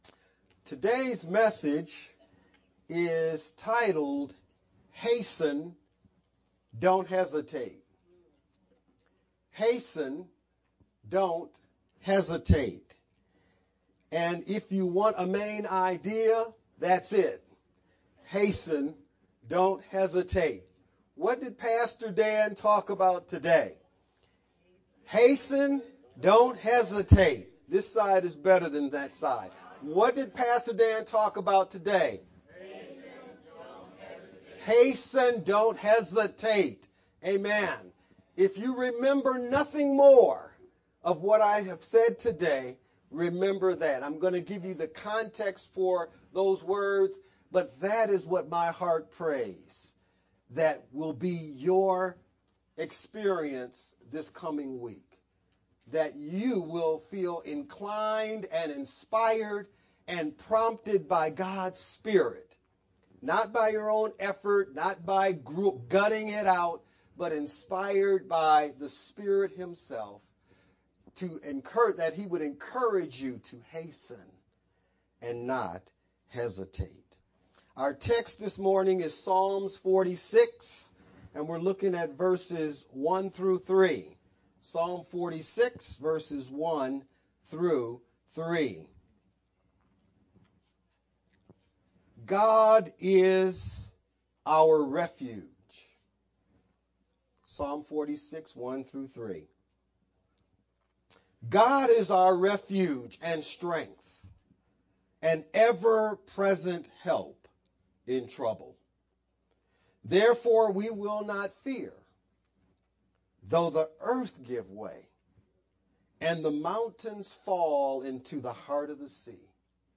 VBCC-Service-Jan-7th-sermon-only_Converted-CD.mp3